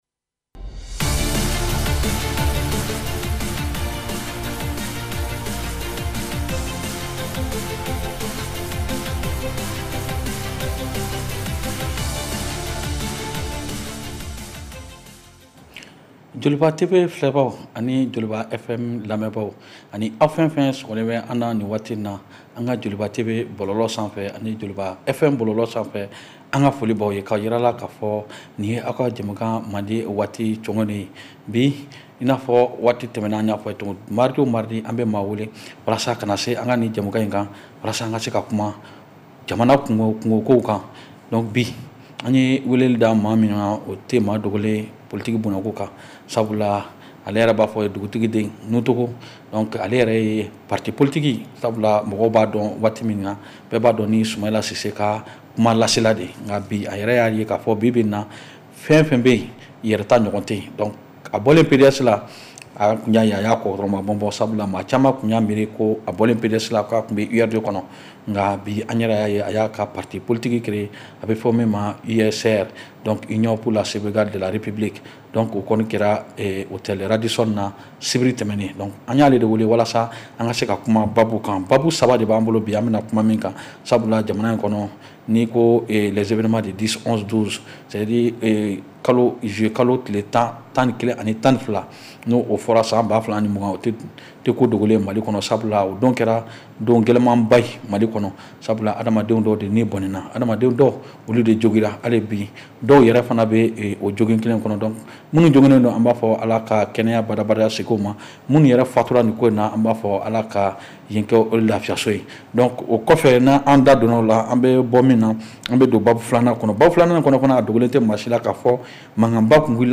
Réécoutez votre émission de débat politique en bambara.